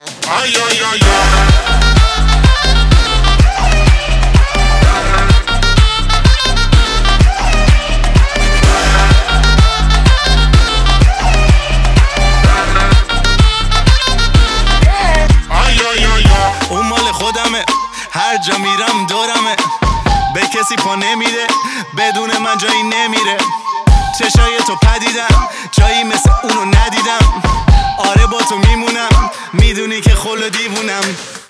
• Качество: 128, Stereo
ритмичные
мужской вокал
заводные
Moombahton
Заводные ритмы на персидском языке (фарси)